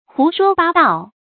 注音：ㄏㄨˊ ㄕㄨㄛ ㄅㄚ ㄉㄠˋ
胡說八道的讀法